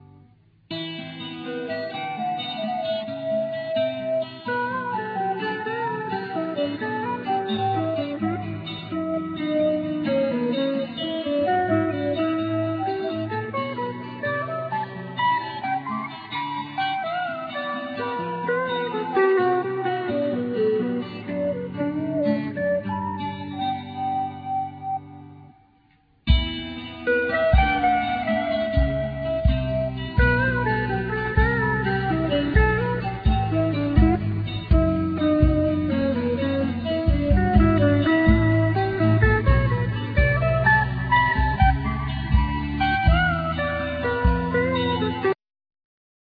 Alto sax,synthesizer
Piano,Keyboards
Guitar,Sitar
Bass
Percussion
Tenor sax
Trumpet
Drums